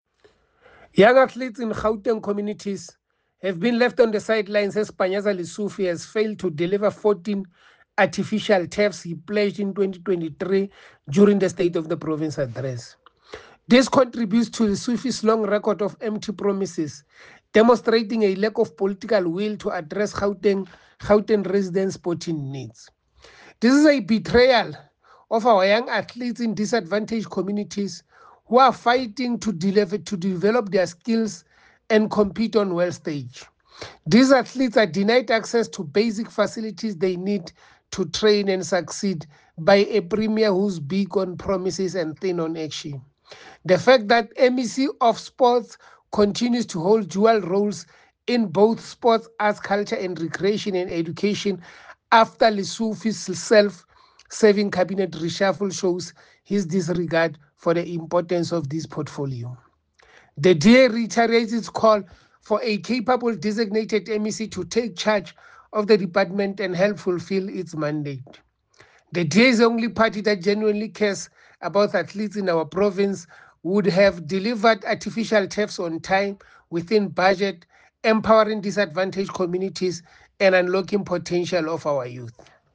soundbite by Kingsol Chabalala MPL.